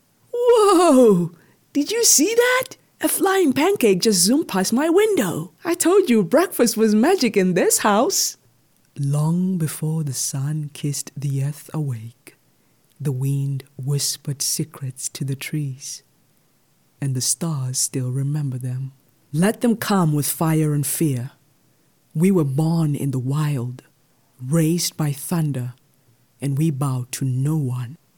authentic, authoritative, bright, captivating, commercial, empathic, resonant, soothing
With a naturally warm and articulate tone, she delivers everything from soulful narration to vibrant commercial reads with clarity and purpose.
CharacterNarrationVO.mp3